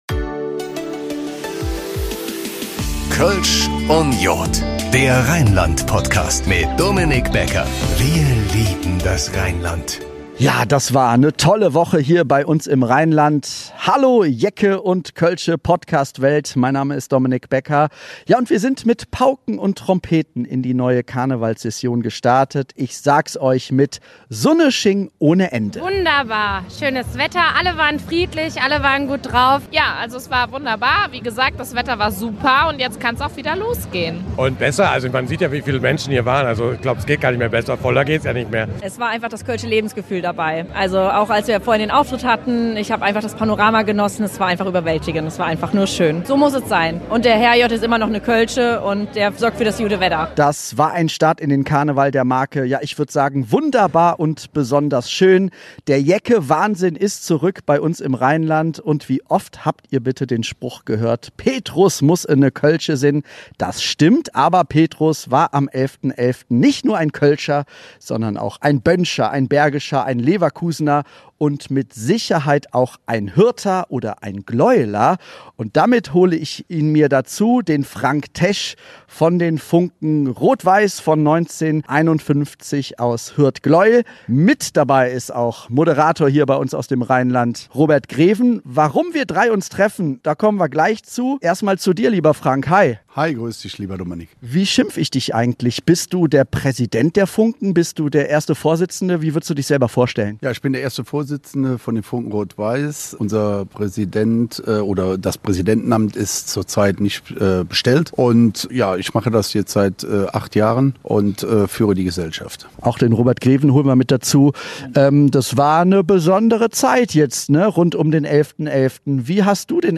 Er nimmt euch nochmal mit in die Kölner Altstadt, zu den Jecken und zu den kölschen Bands.